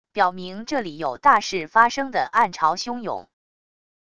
表明这里有大事发生的暗潮汹涌wav音频